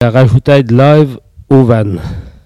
Patois - archives
Catégorie Locution